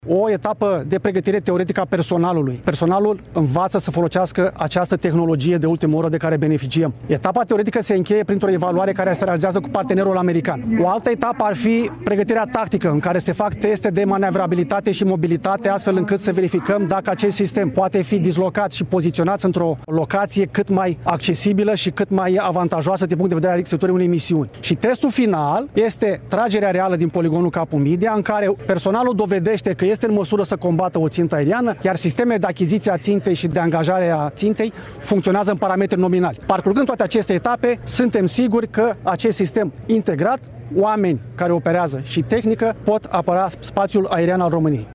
Reprezentant al MApN: „Testul final este tragerea reală din poligonul Capu Midia în care personalul dovedește că este în măsură să combată o țintă aeriană”